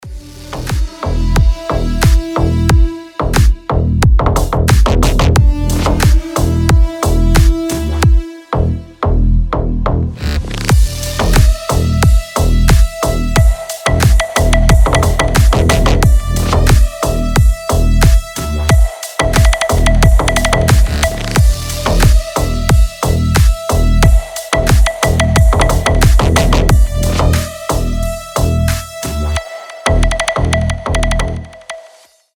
клубные
восточные , сирена , басы